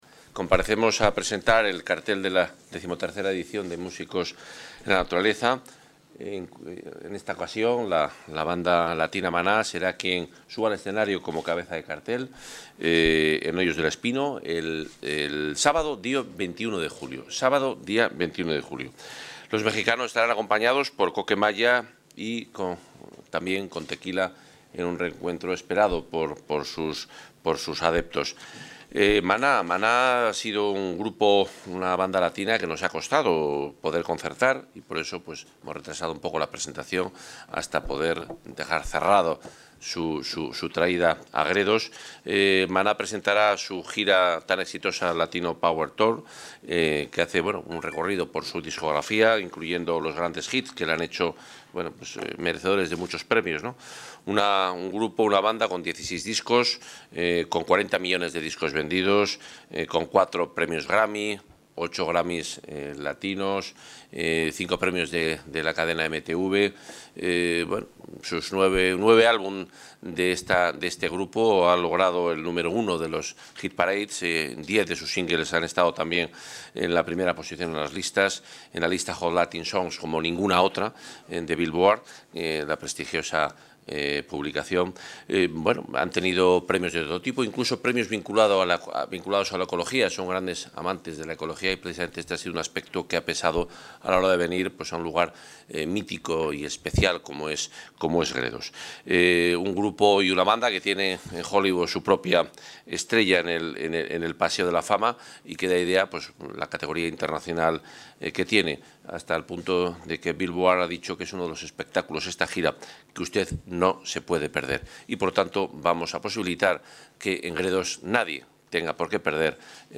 Intervención del consejero de Fomento y Medio Ambiente.